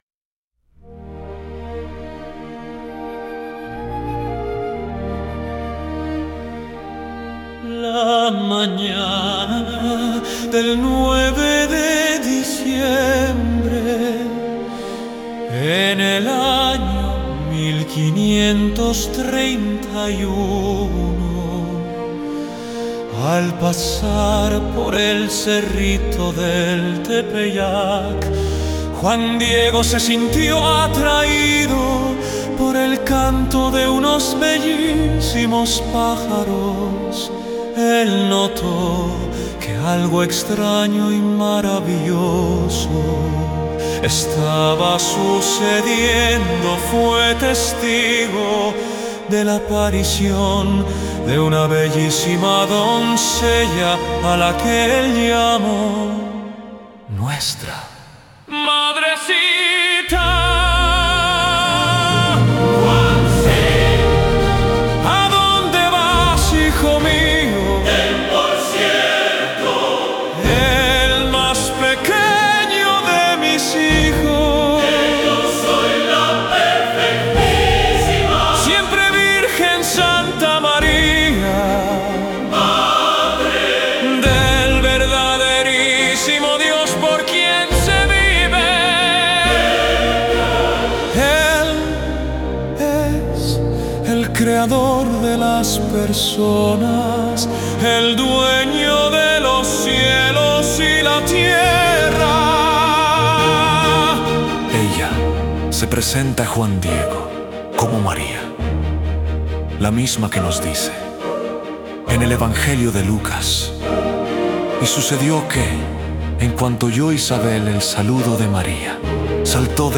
Escucha Música Muestra 3: opera italiana